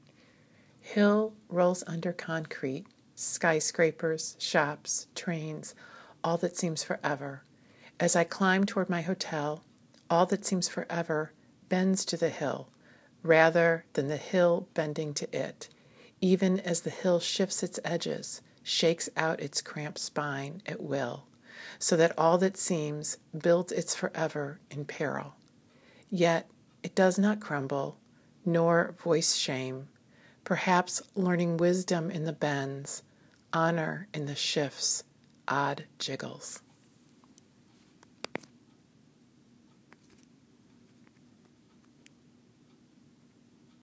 So here it is – adding a voice recording to my poetry posts.